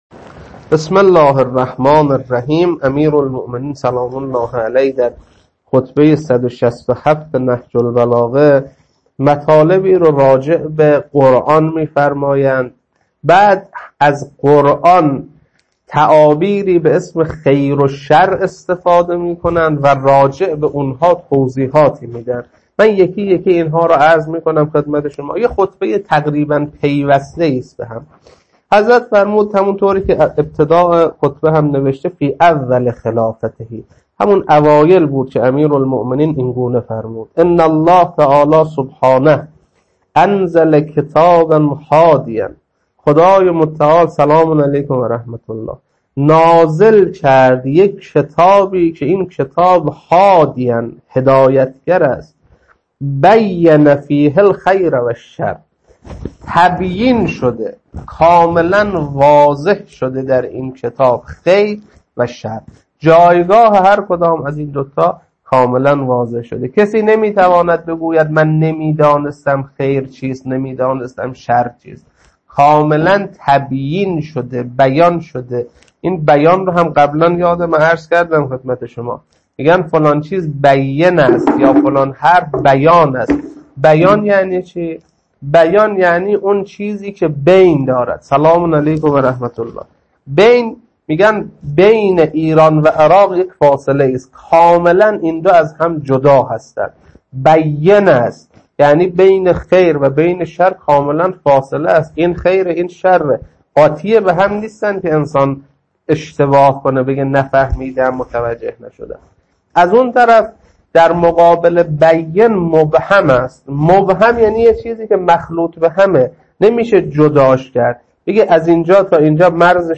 خطبه 167.mp3
خطبه-167.mp3